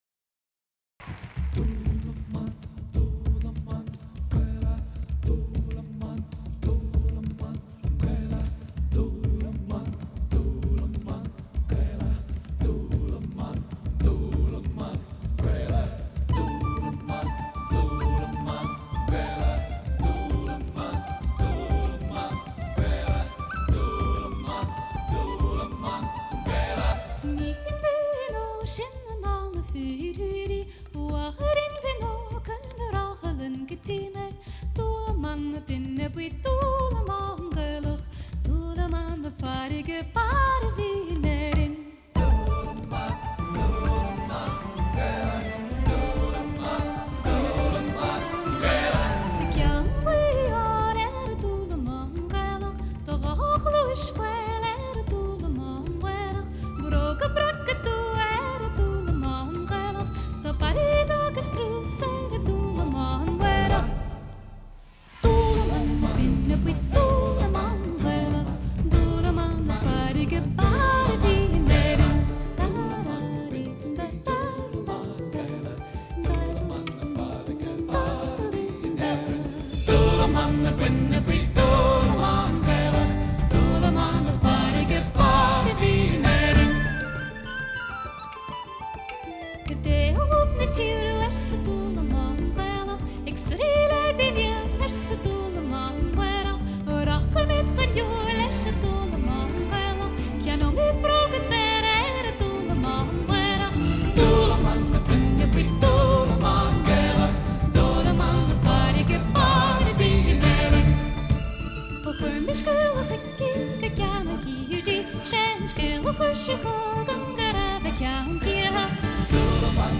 Beautiful live version